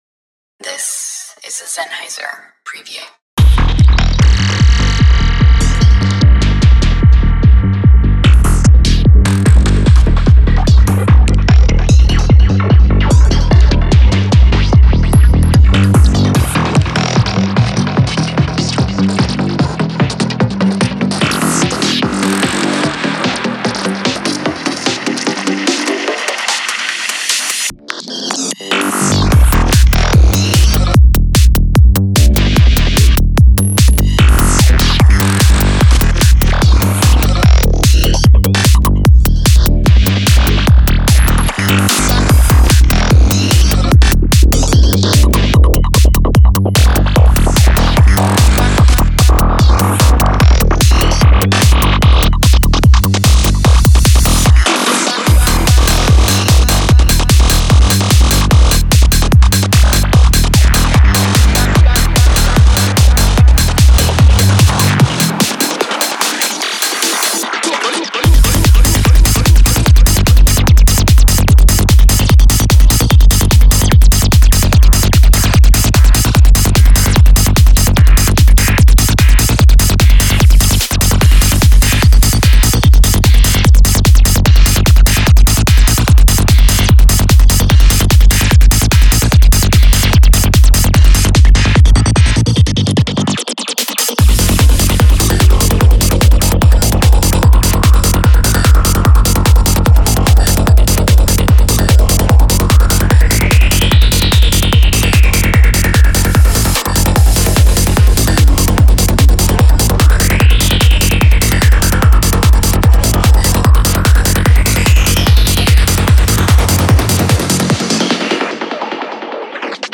張り詰めた攻撃的なリズムを持つ150種類のドラムループは、外科手術のような精度でスナップします。
うねり、伸縮し、ドラムループと完璧にシンクロすることで、真のPsytranceサウンドを包み込みます。
グリッチを多用した質感豊かなサウンドスケープは、粒状感のあるテクスチャーを描き出します。
スクエルチ感、デジタルな摩擦、緻密なエディット。
デモサウンドはコチラ↓
Genre:Psy Trance